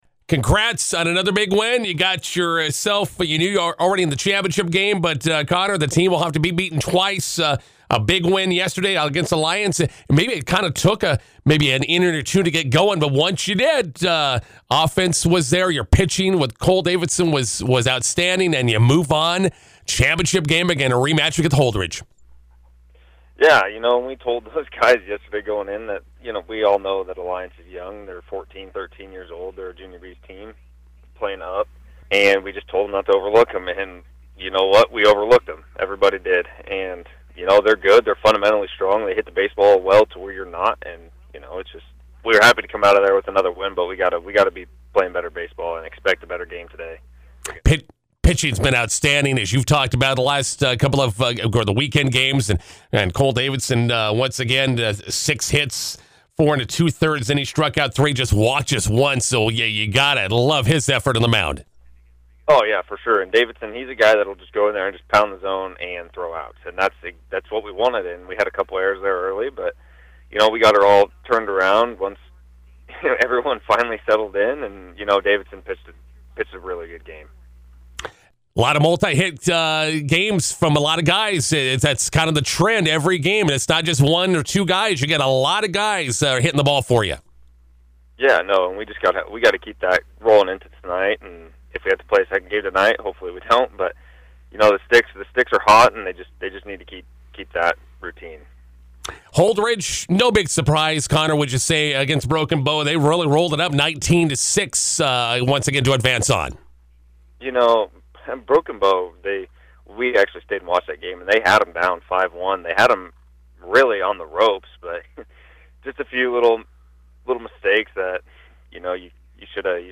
INTERVIEW: First Central Bank Jr’s are one win away for B6 area baseball tournament title, face Holdrege tonight.